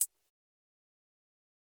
Metro Hi-Hat.wav